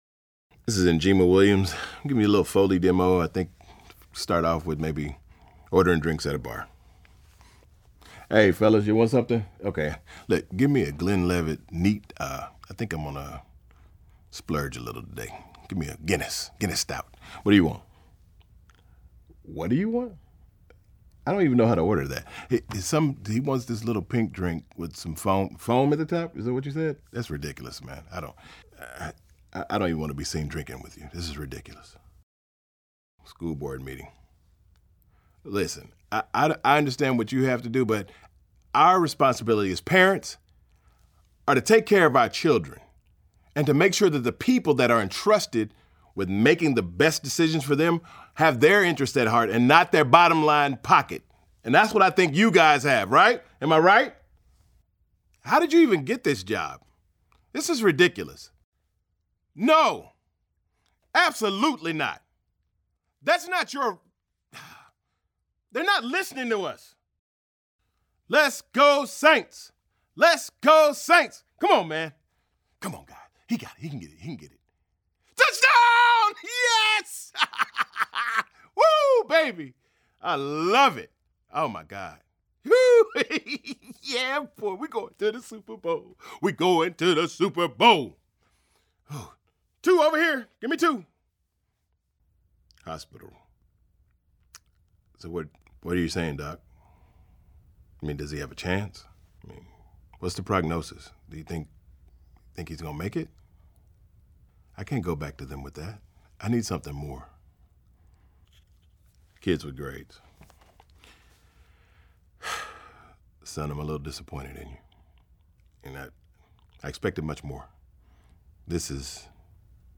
atlanta : voiceover